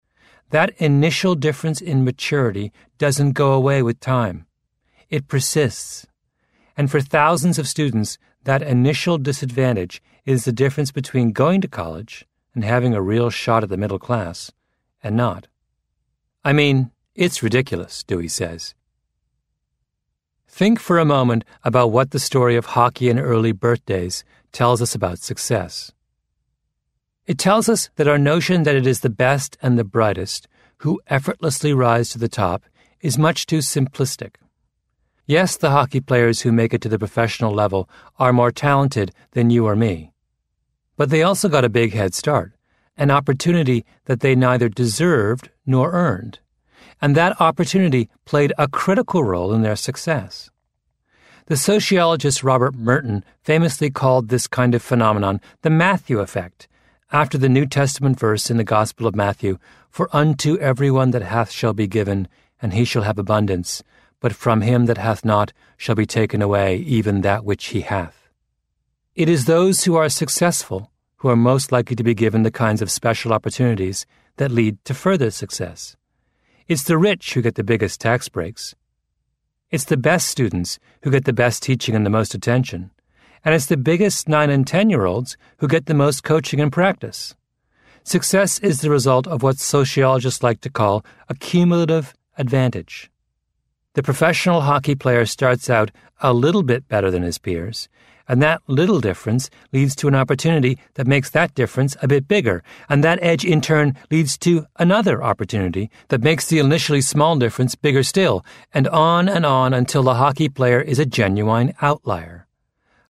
在线英语听力室异类之不一样的成功启示录 第19期:累积效应的听力文件下载, 《异类:不一样的成功启示录Outliers:The Story of Success》是外文名著，是双语有声读物下面的子栏目，栏目包含中英字幕以及地道的英语音频朗读文件MP3，通过学习本栏目，英语爱好者可以懂得不一样的成功启示，并在潜移默化中挖掘自身的潜力。